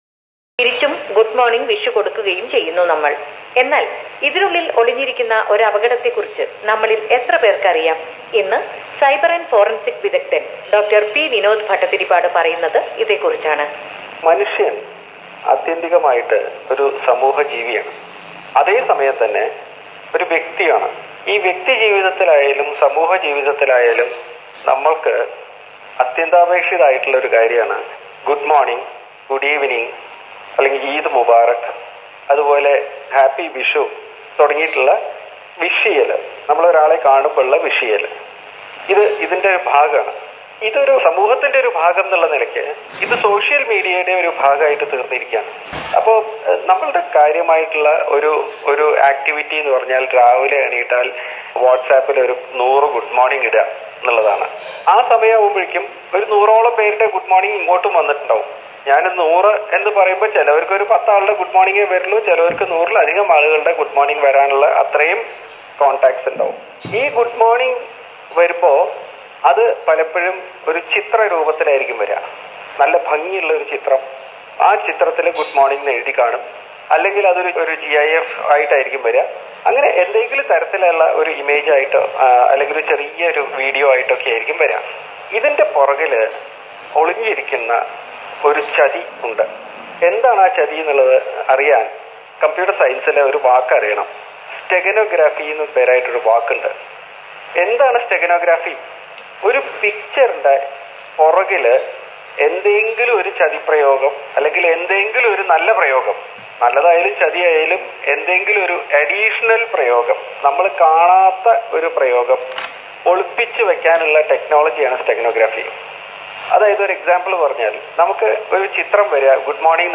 സൈബർ ഫോറൻസിക് വിദഗ്ധൻ